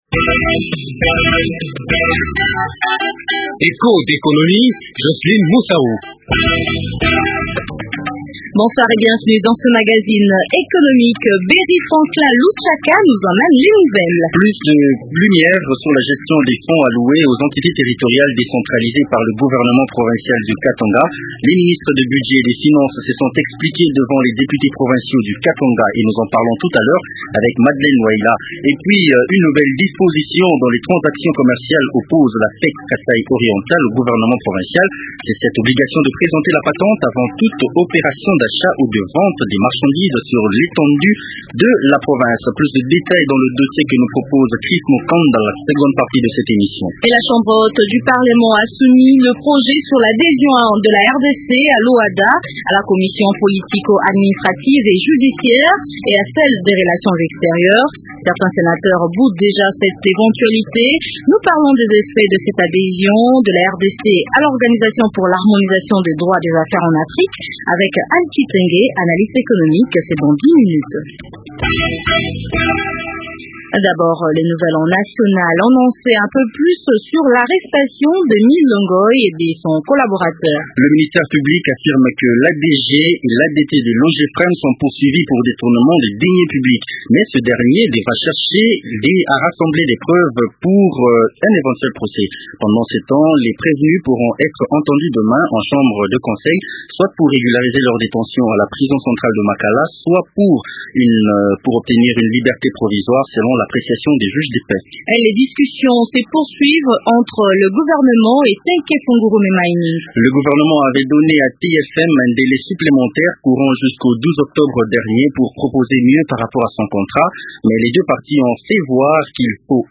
Interview à suivre dans cette émission. Plus de lumière sur la gestion des fonds alloués aux entités territoriales décentralisées par le gouvernement provincial du Katanga ; les ministres du budget et des finances se sont expliqués devant les députés provinciaux du Katanga.